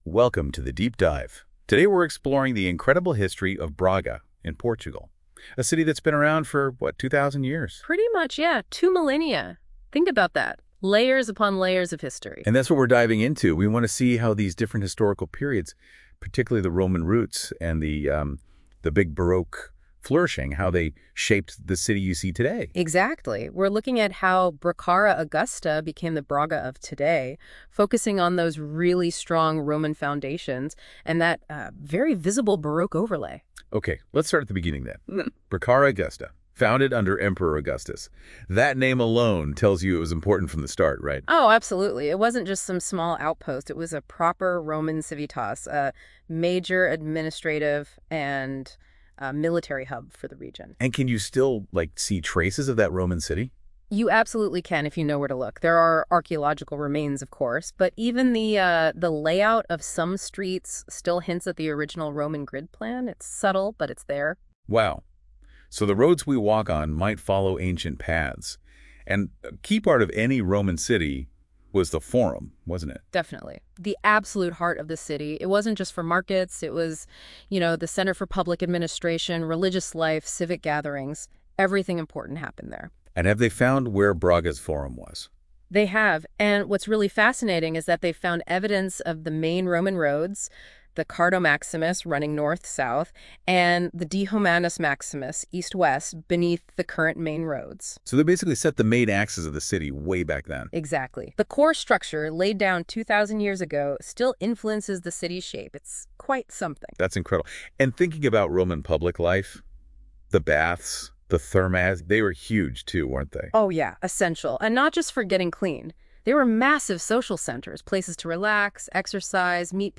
(Deep dive generated using NotebookLM and curated sources)